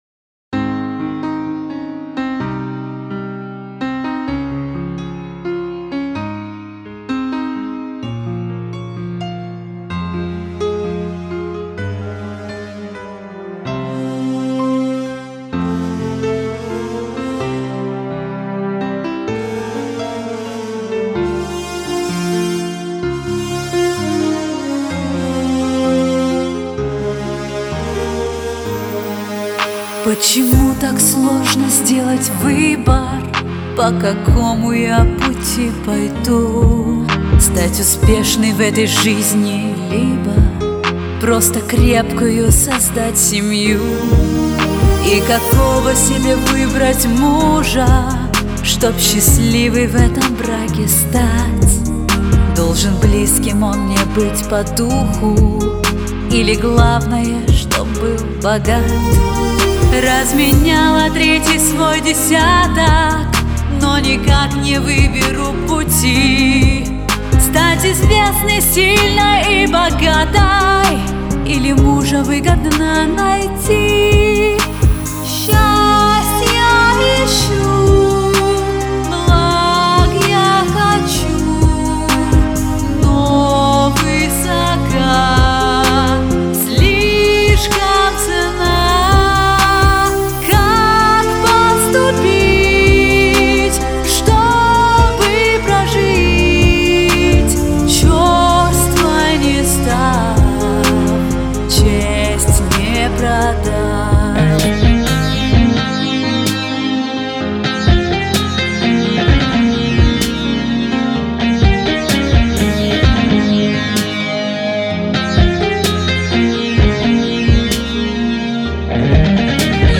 Песня в стиле R&B Ballad о нелегком выборе девушек.